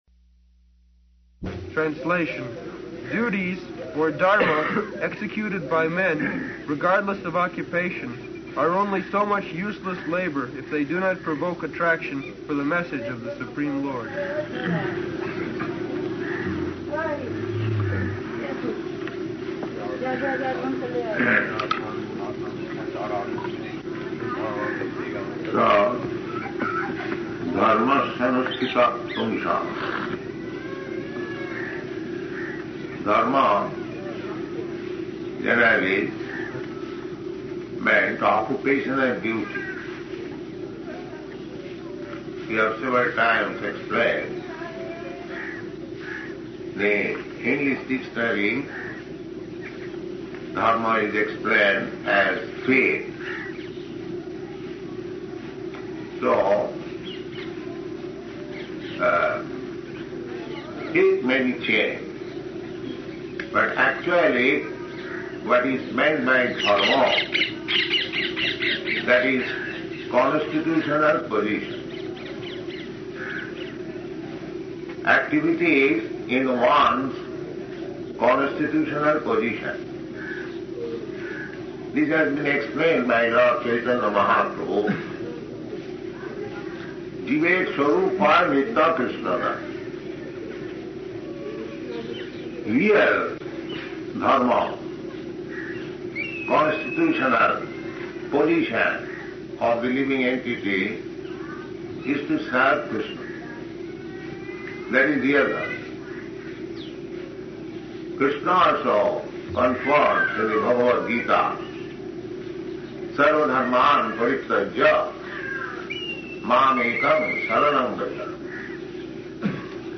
Srila Prabhupada Lectures | The Ultimate Gain in Life | Srimad Bhagavatam 1-2-6 | Delhi – In Service of Srimad Bhagavatam™ – Podcast